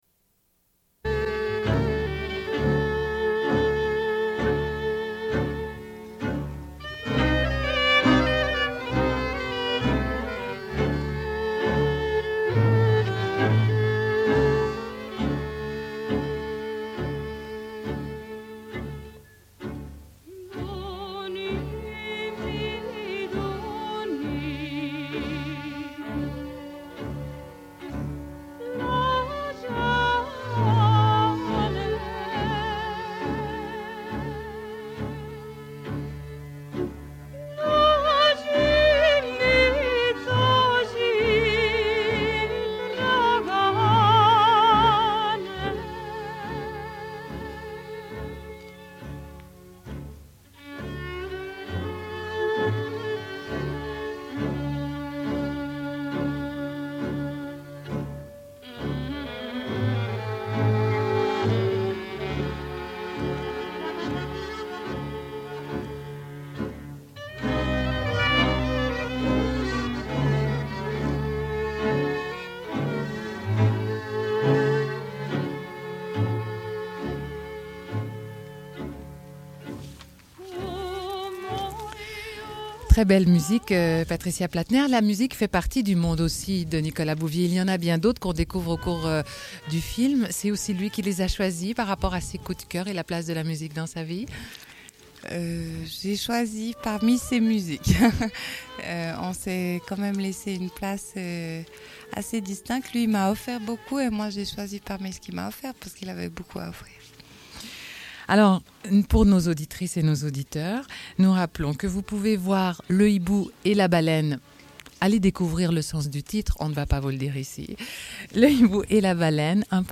Bulletin d'information de Radio Pleine Lune du 19.05.1993 - Archives contestataires
Une cassette audio, face B29:56